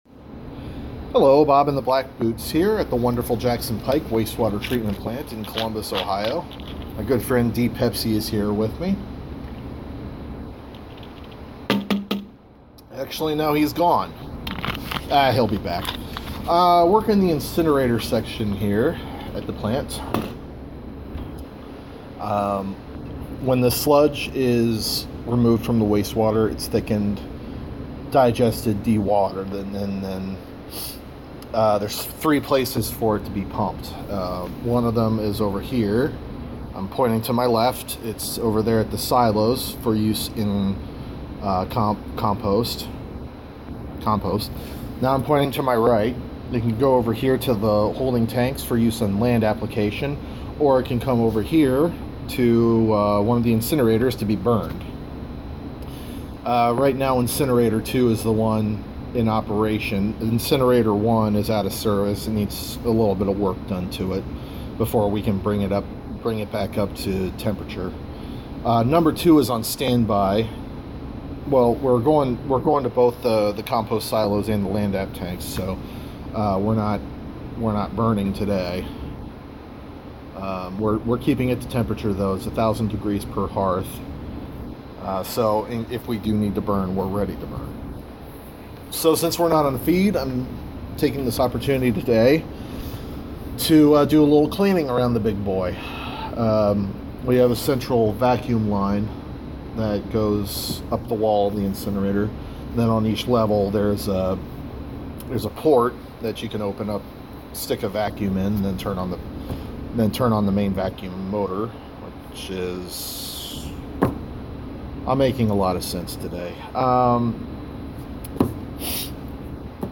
Vacuuming the incinerator.